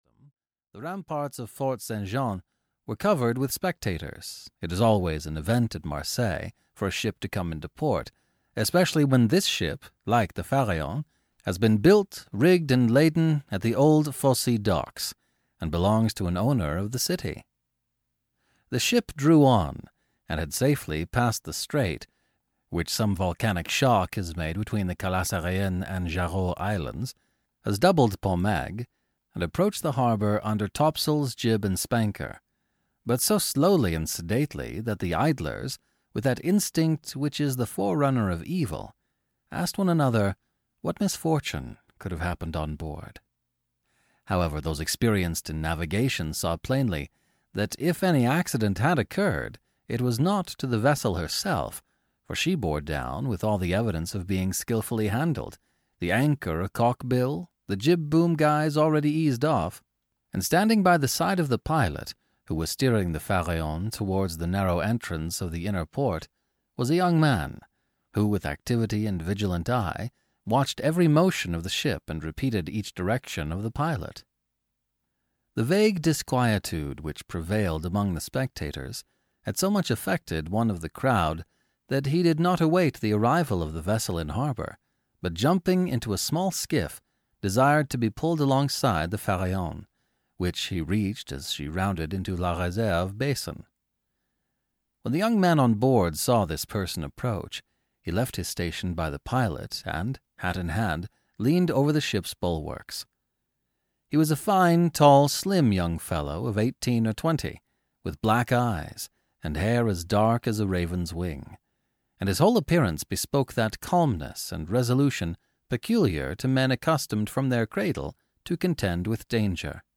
audiokniha
Ukázka z knihy